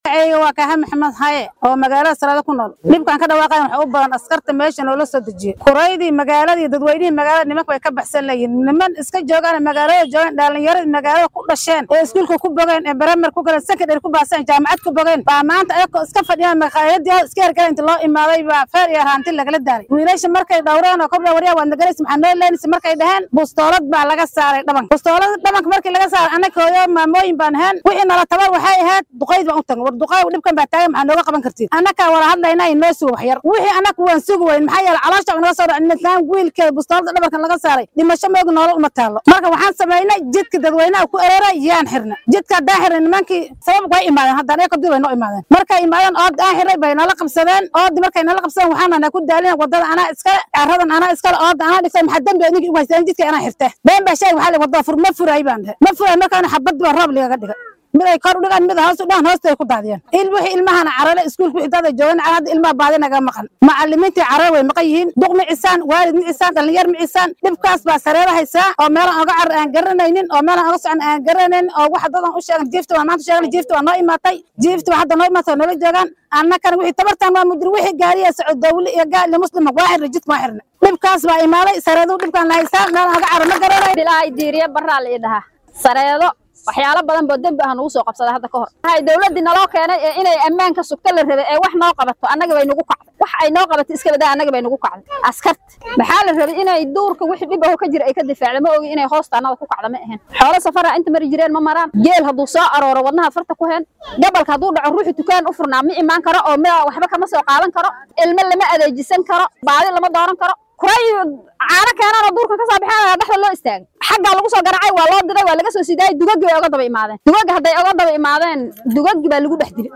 Xaaladda Sareedo ayaa hadda deggan iyadoo qaar ka mid ah shacabka oo la hadlay warbaahinta Star ay cabashadooda sidatan u muujiyeen.